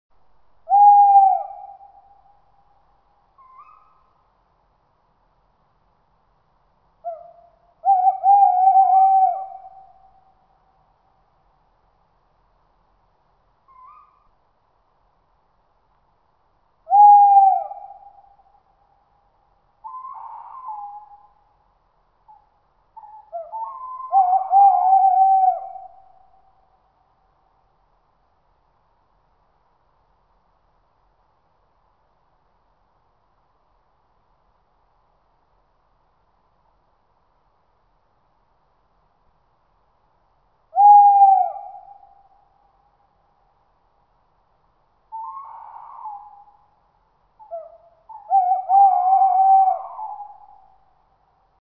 Звук крика совы вдали